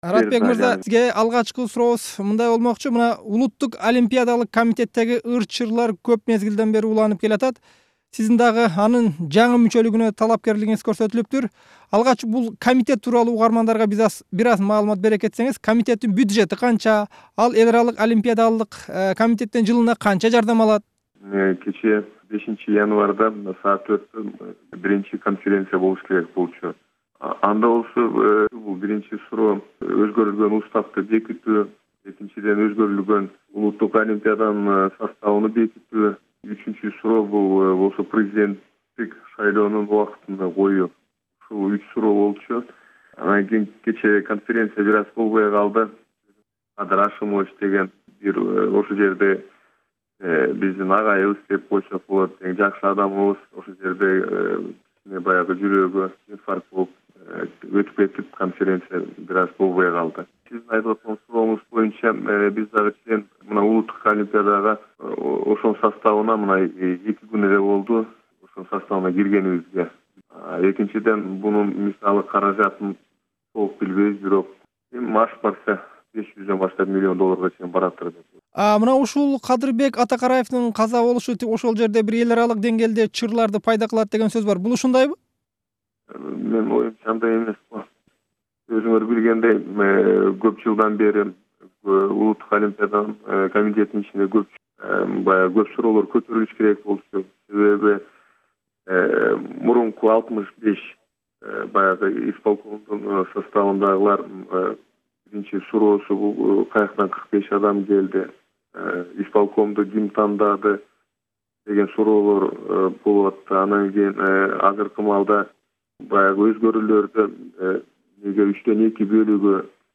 Раатбек Санатбаевдин соңку маеги